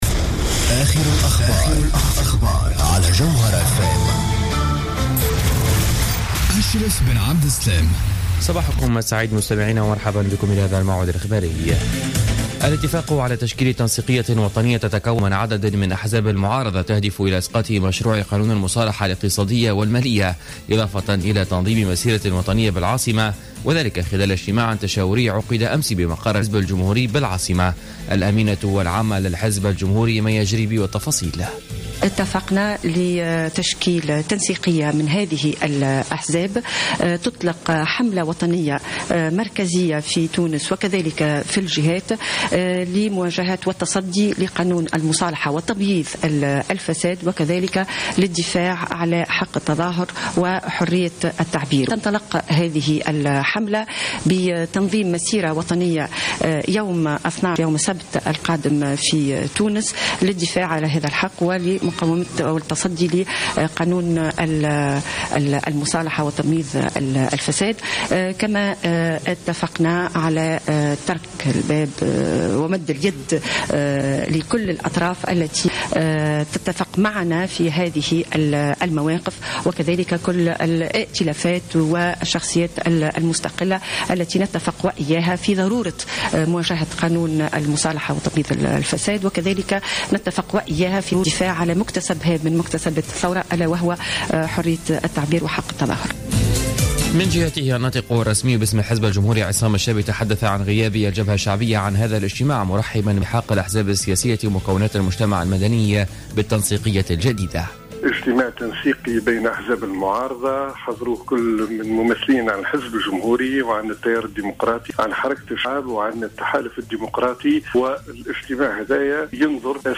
نشرة أخبار السابعة صباحا ليوم السبت 5 سبتمبر 2015